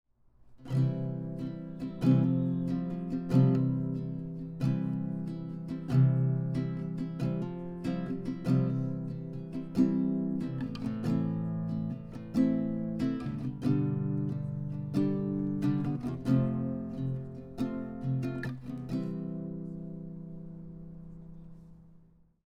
I ended up getting the Yamaha CGS102A, a half size nylon string acoustic guitar that has a clean, classic look with a great sound at a very reasonable price.
What I liked about the guitar is the full, warm and clean sound.
I’m using a Focusrite Scarlett audio interface to record these examples.
Chords 1 audio example
CGS102A-chords-1.mp3